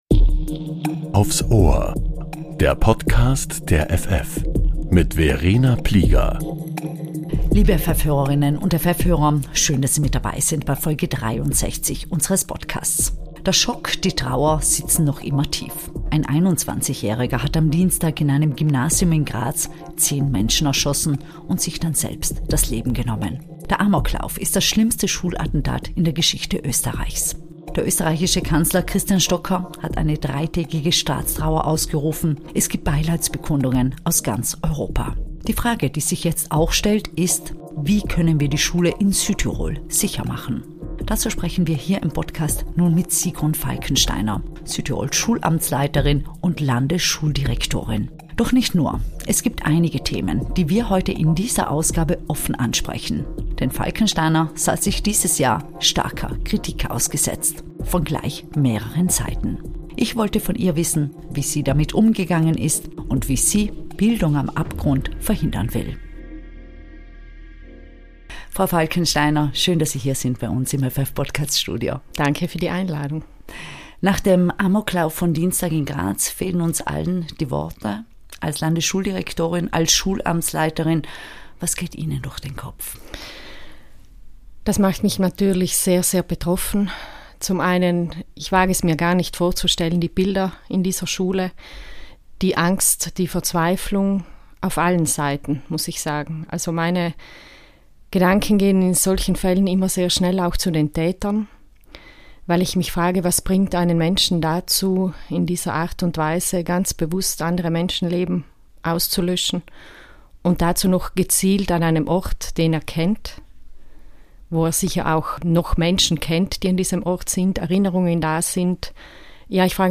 Landesschuldirektorin Sigrun Falkensteiner spricht offen, was die vergangenen Monate mit ihr gemacht haben